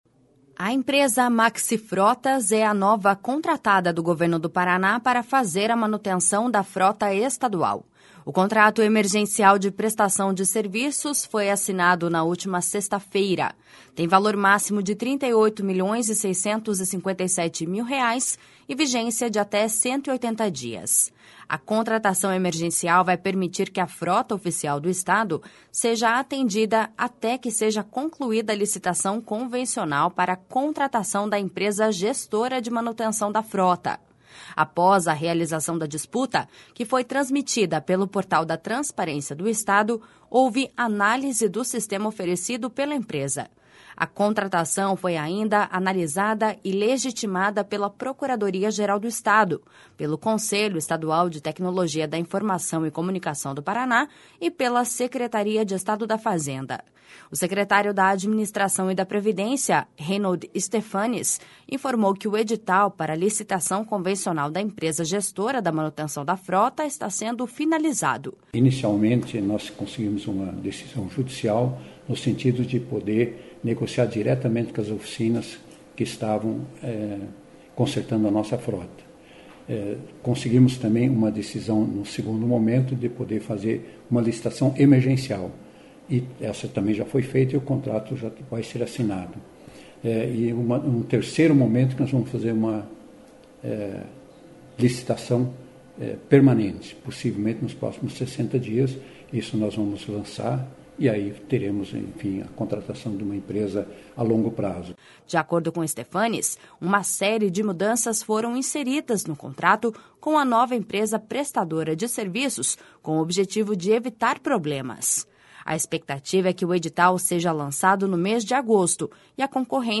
// SONORA REINHOLD STEPHANES // De acordo com Stephanes, uma série de mudanças foram inseridas no contrato com a nova empresa prestadora de serviços com o objetivo de evitar problemas.